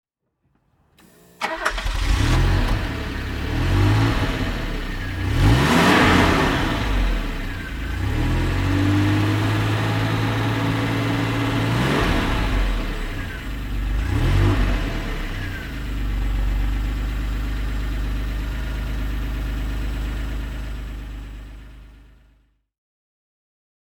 This vehicle report portrays Bowie's Volvo and summarizes the history of the 262 C. A sound sample and historical pictures are also included.
Volvo 262 C (1981) - Starten und Leerlauf